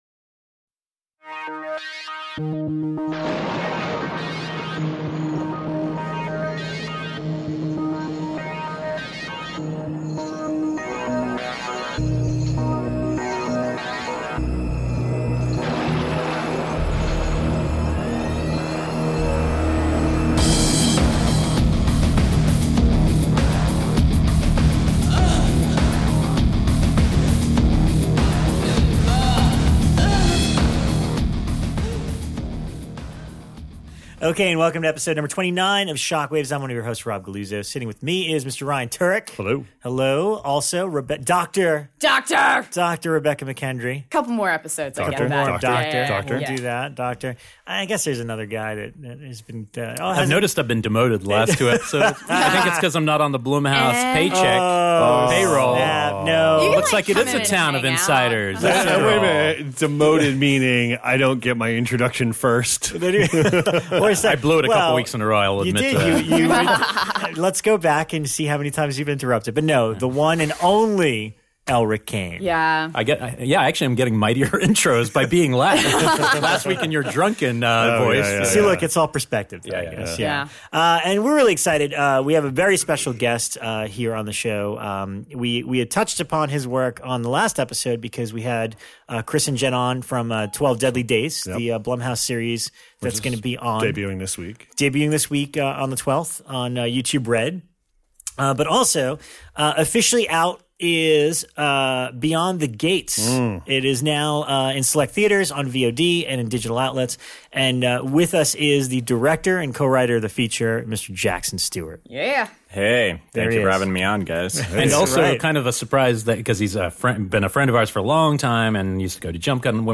Kick back, relax and enjoy this candid conversation with this emerging new horror filmmaker!